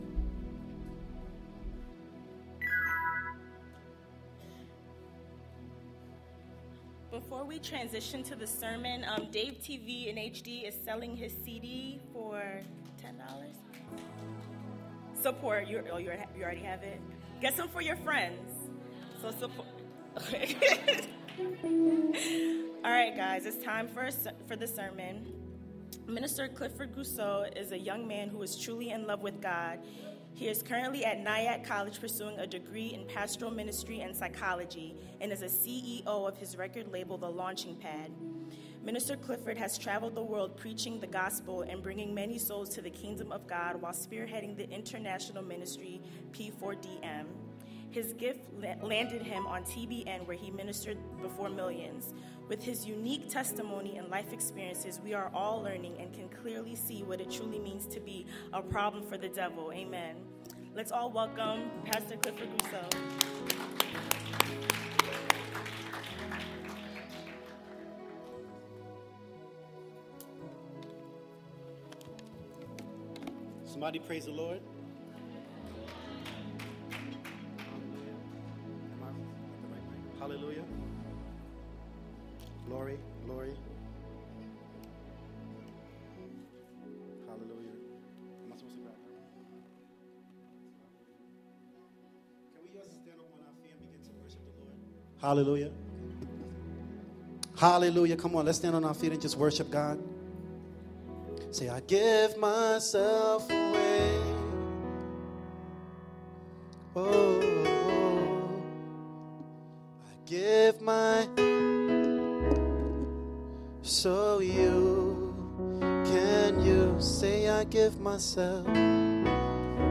2014 Youth Conference: Lights Out – Day 3